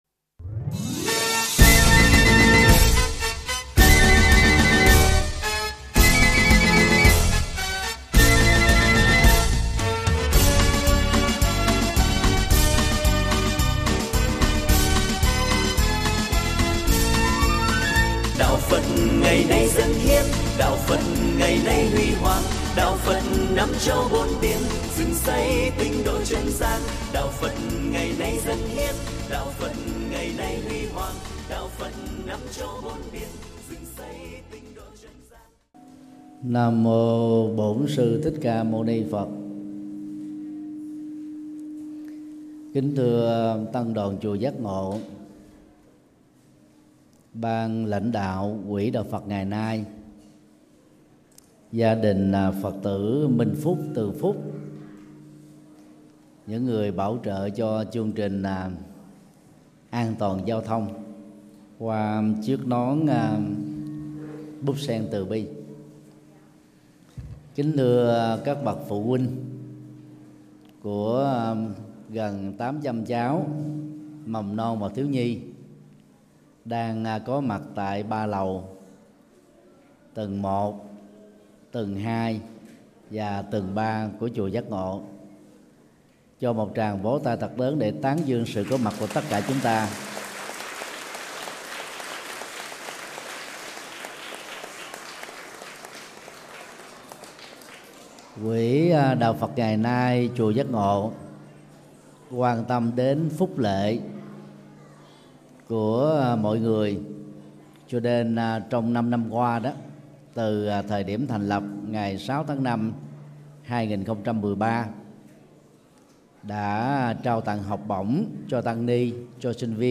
Bài mp3 Pháp Thoại An Toàn Giao Thông – Thượng Tọa Thích Nhật Từ giảng trong khóa tu Búp Sen Từ Bi 15: Trao tặng nón bảo hiểm cho các bé, tại chùa Giác Ngộ